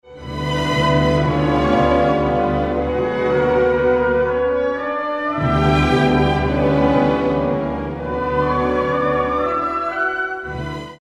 sovrapposizione
del tema di valzer al trio   bt. 81-85 legni + archi 13 esempio 13 di partitura (formato PDF)
esempio 13 orchestrale (formato MP3) esempio multimediale (formato formato flash)